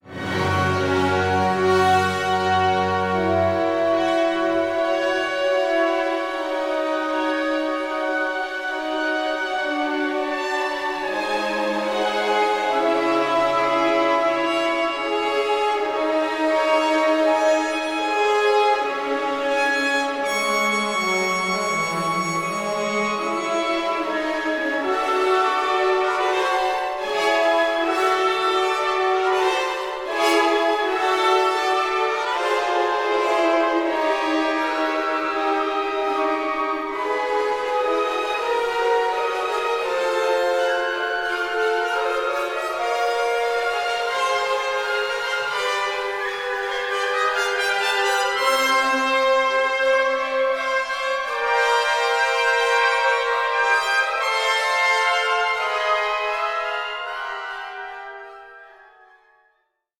tone poem
it’s a bold, optimistic and passionate work